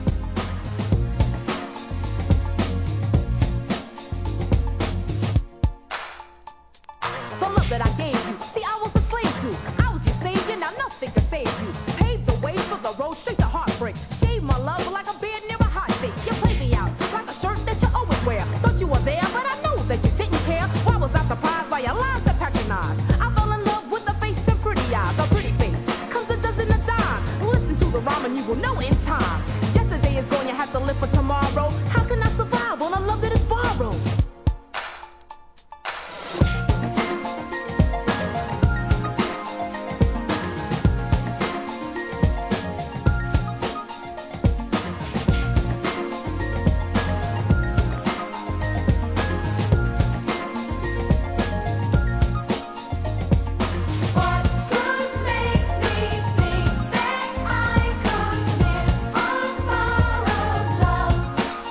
HIP HOP/R&B
NEW JACK SWING!!